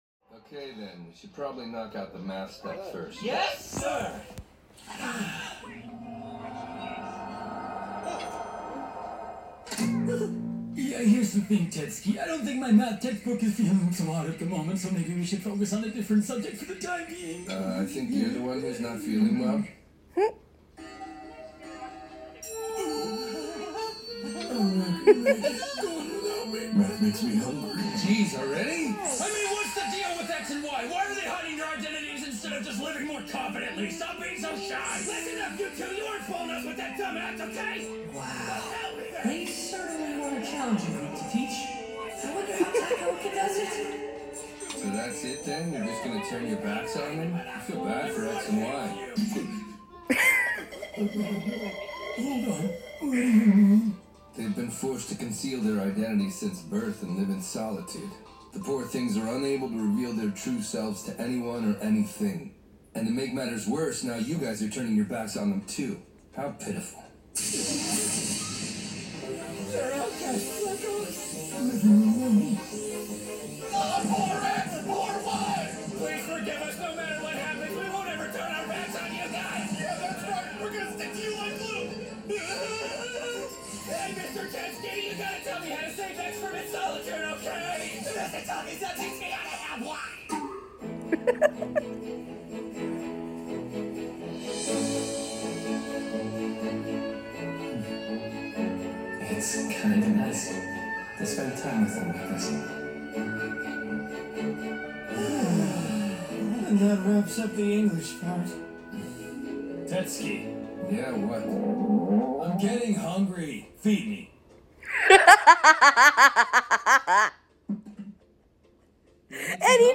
excuse my annoying laugh, my apologies.